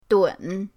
dun3.mp3